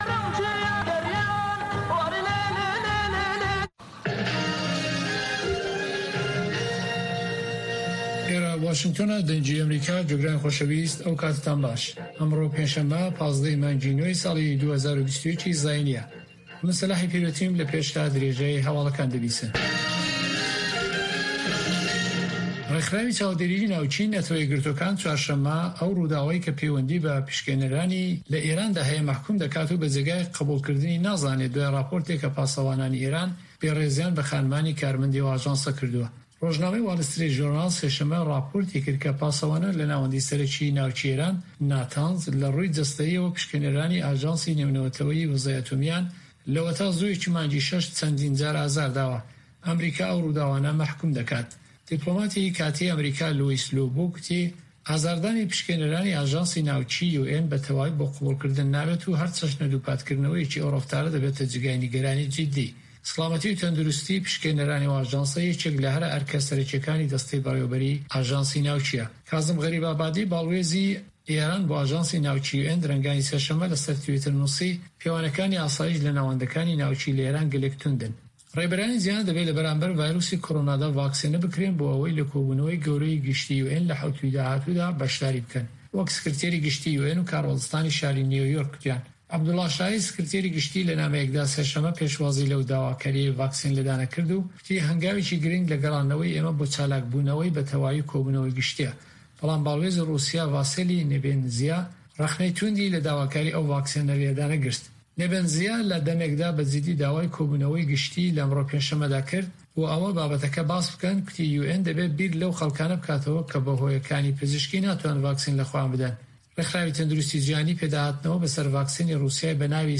Nûçeyên 1’ê şevê
Nûçeyên Cîhanê ji Dengê Amerîka